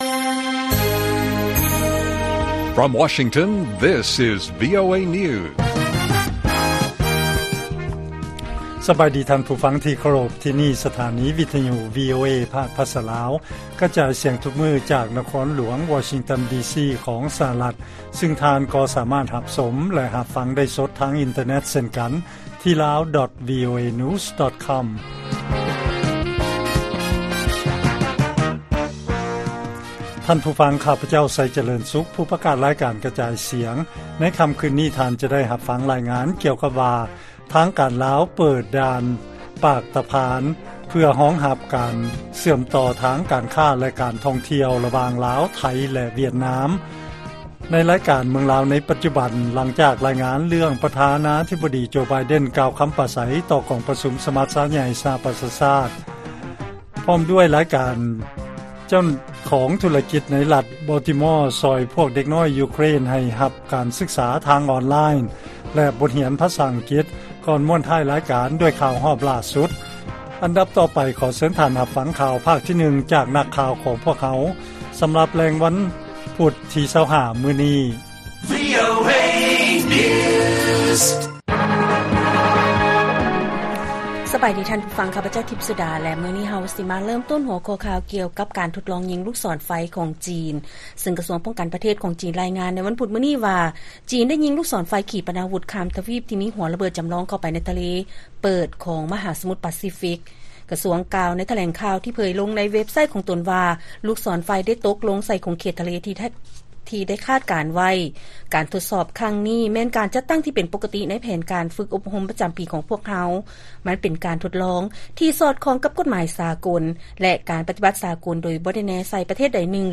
ລາຍການກະຈາຍສຽງຂອງວີໂອເອລາວ: ຈີນ ທົດລອງຍິງລູກສອນໄຟ ເຂົ້າສູ່ເຂດ 'ທະເລເປີດ' ຂອງມະຫາສະໝຸດປາຊີຟິກ